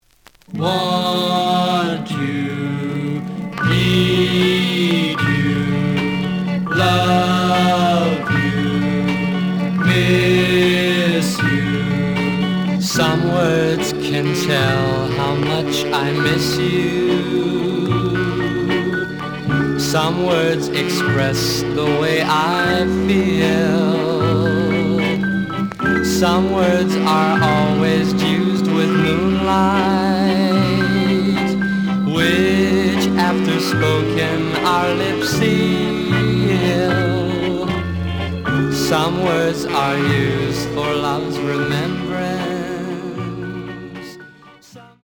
試聴は実際のレコードから録音しています。
●Genre: Rock / Pop